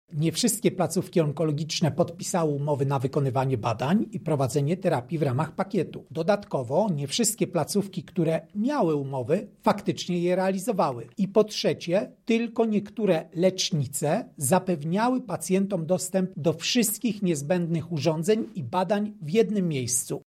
– Często wina leżała po stronie samych szpitali – mówi prezes NIKu, Krzysztof Kwiatkowski.